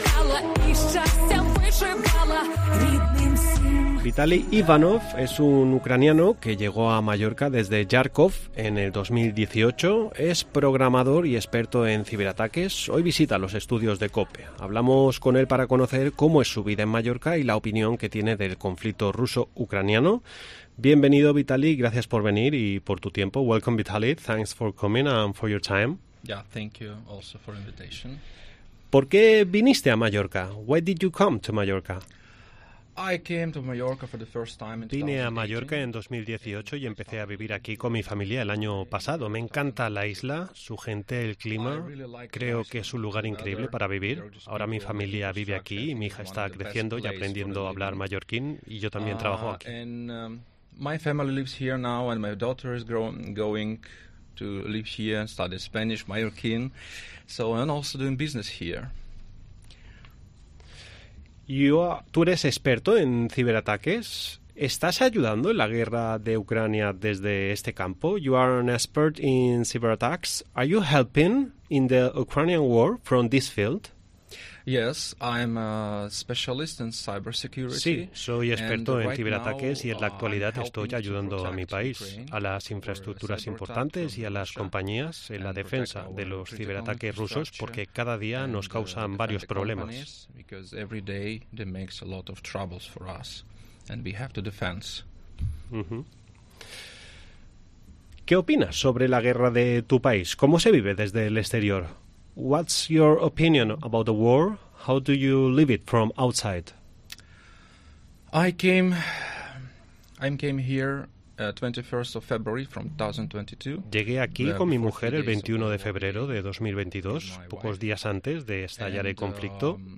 Hoy visita los estudios de Cope. Hablamos con él para conocer cómo es su vida en Mallorca y la opinión que tiene del conflicto ruso ucraniano.